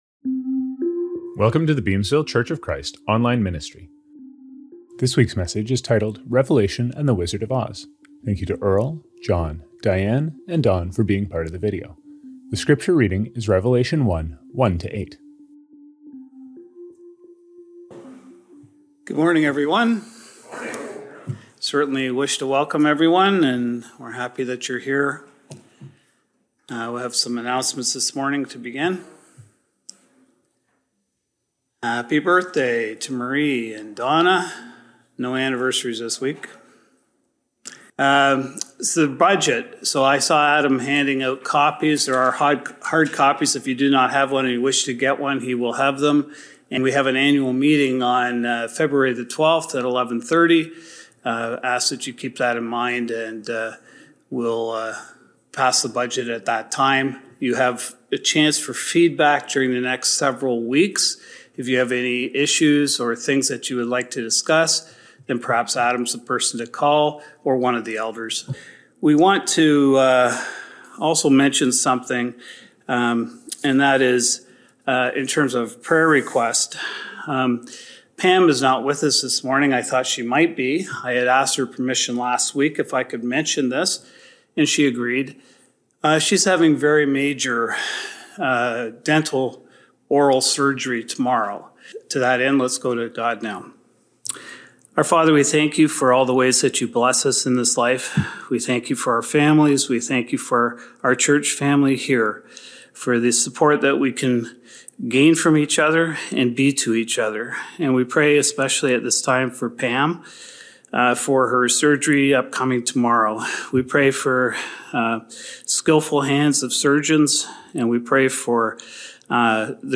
Scriptures from this service: Communion - Hebrews 10:8-14 (ESV); Mark 12:30-31 (ESV); 1 Corinthians 11:23-26 (ESV).